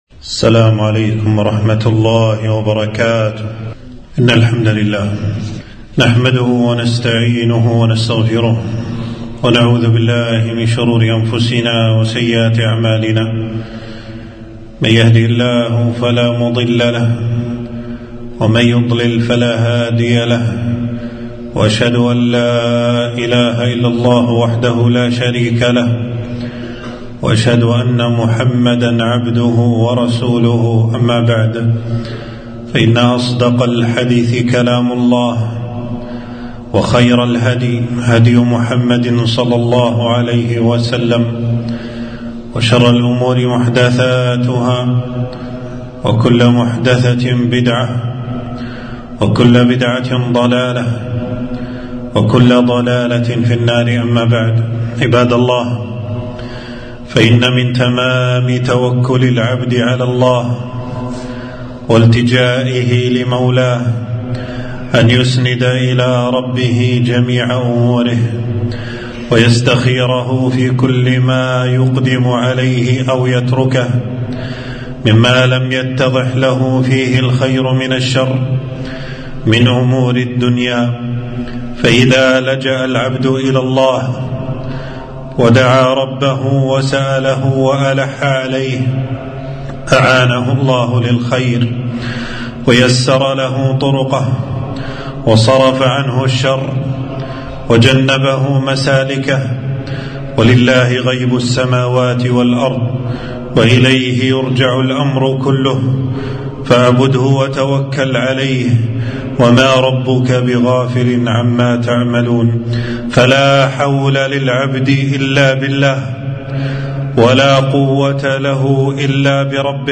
خطبة - صلاة الاستخارة استحبابها وفضلها